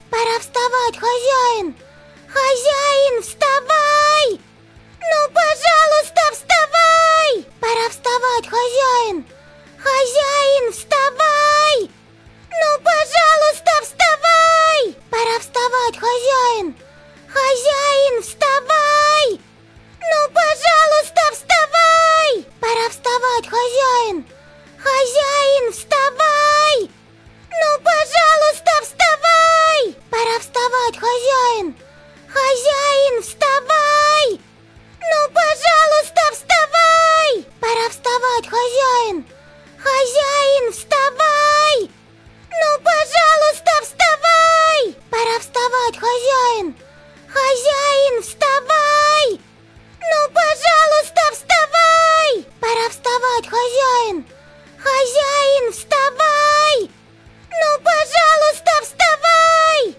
Тихий и плавный звук создаст атмосферу уюта и безопасности.